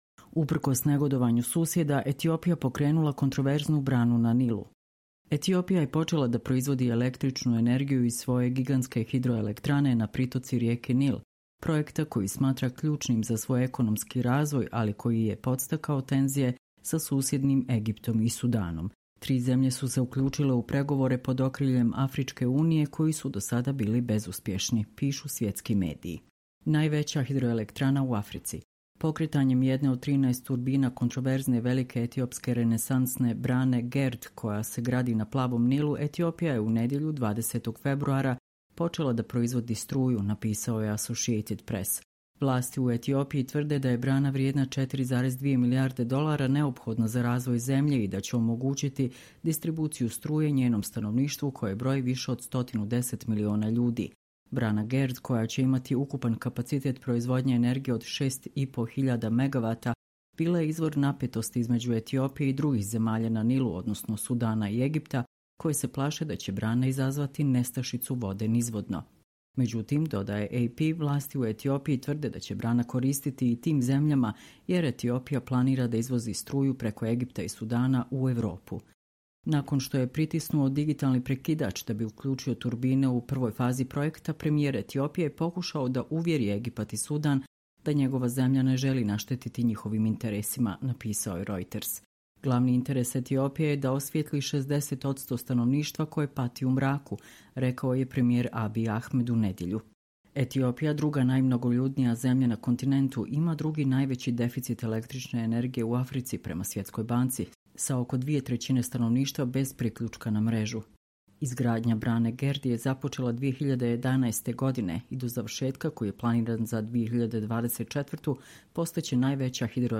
Čitamo vam: Uprkos negodovanju susjeda, Etiopija pokrenula kontroverznu branu na Nilu